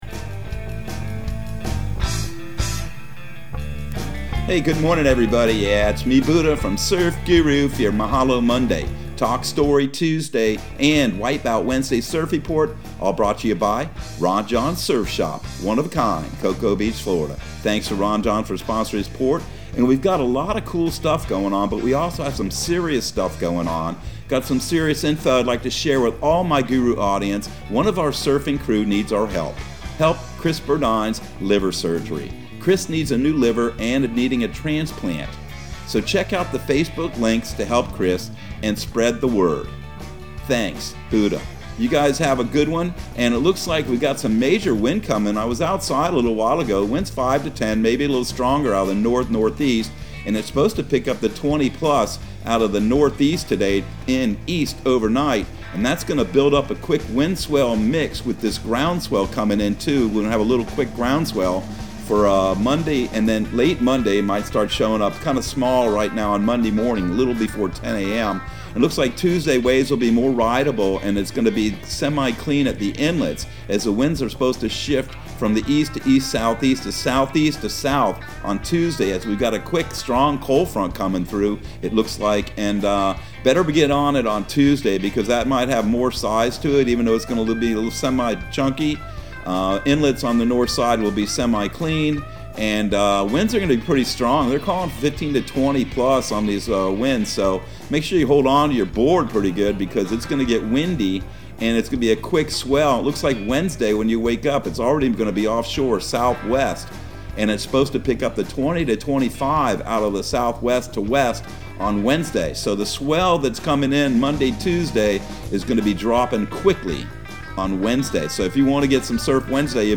Surf Guru Surf Report and Forecast 12/20/2021 Audio surf report and surf forecast on December 20 for Central Florida and the Southeast.